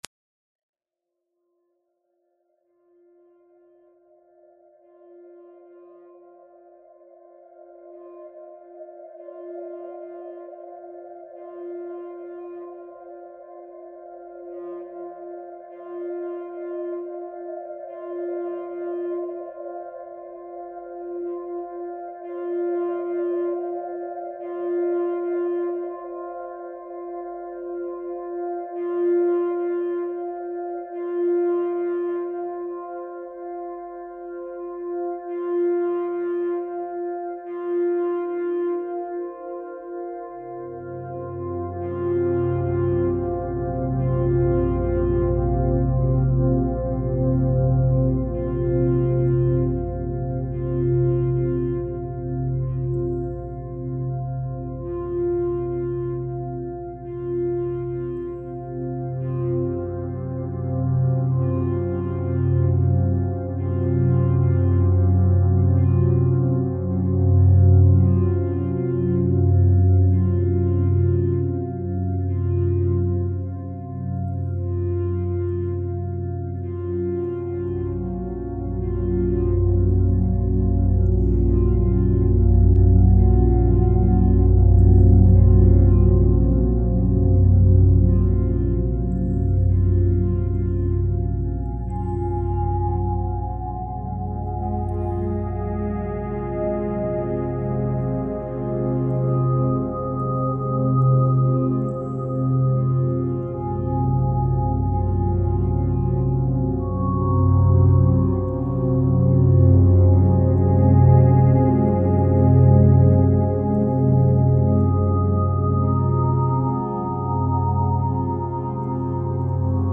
File under: Ambient / Dark Ambient